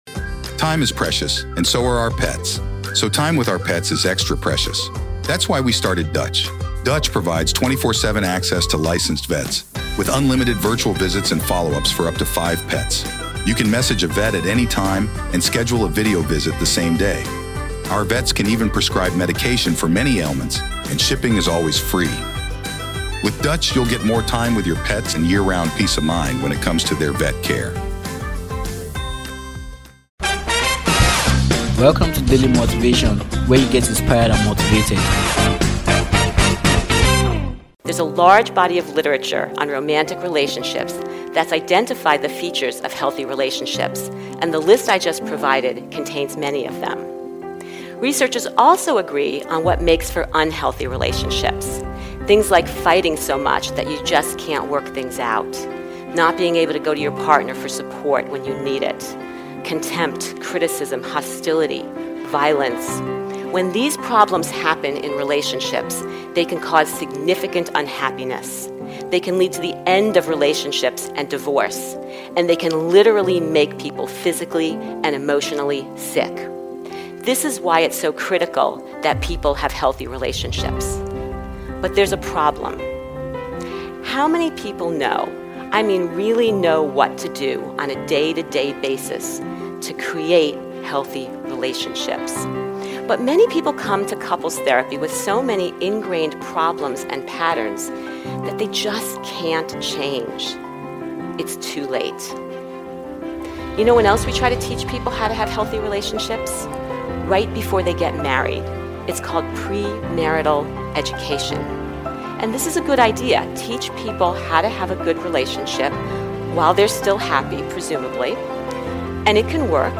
Motivational speech